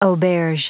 Prononciation du mot auberge en anglais (fichier audio)
Prononciation du mot : auberge